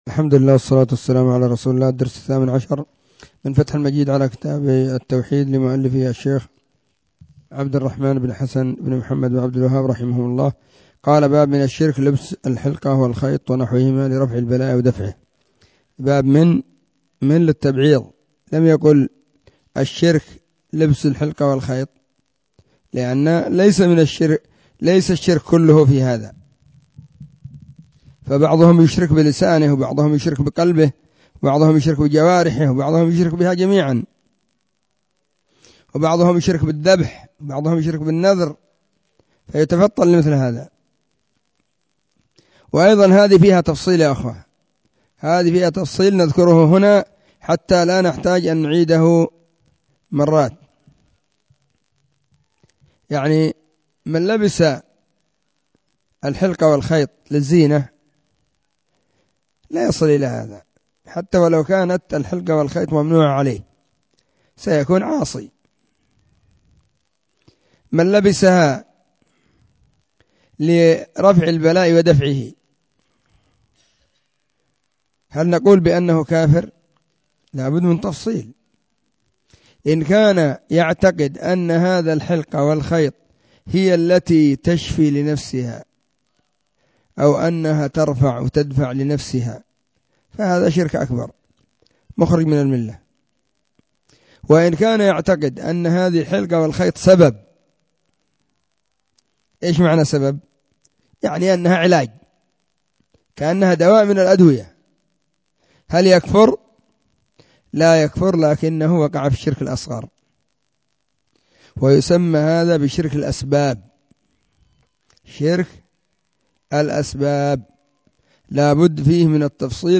📢 مسجد الصحابة – بالغيضة – المهرة، اليمن حرسها الله.
فتح_المجيد_شرح_كتاب_التوحيد_الدرس_18.mp3